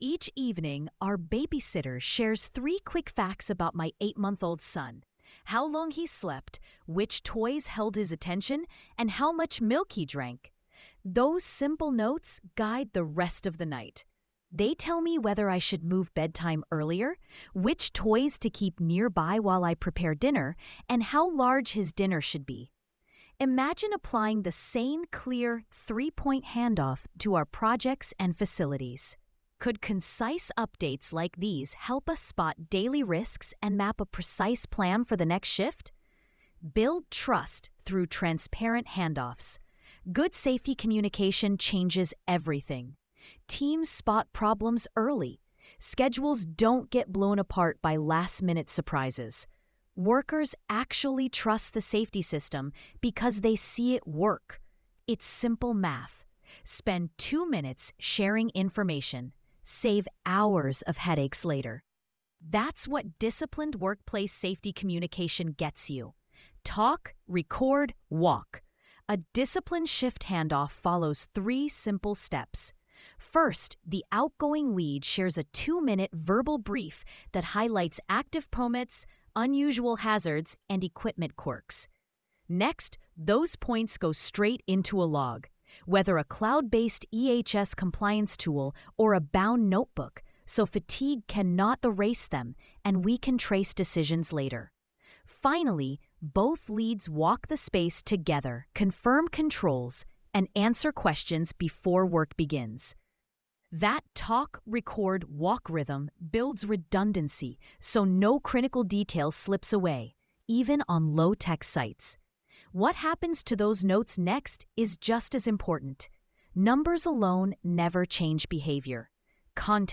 sage_gpt-4o-mini-tts_1x_2025-07-09T07_08_47-886Z.wav